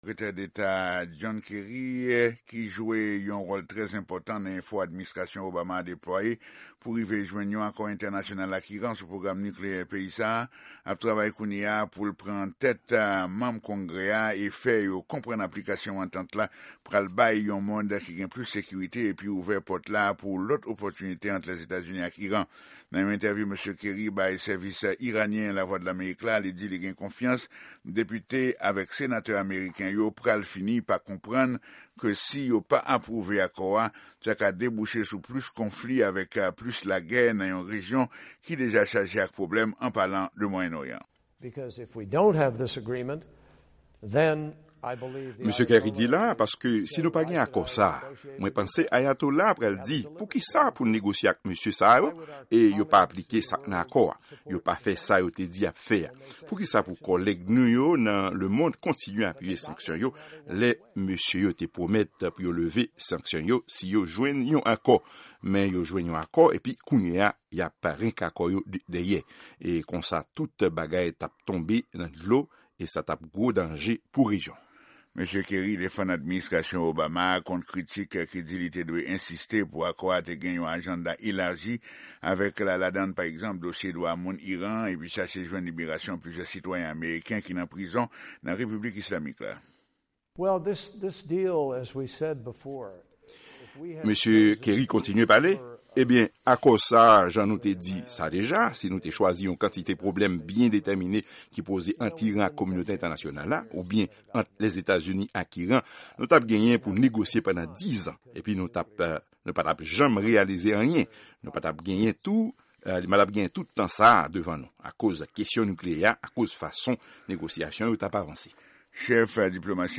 Entèvyou Sekretè Deta John Kerry ak Lavwadlamerik sou Akò Nikleyè ak Iran an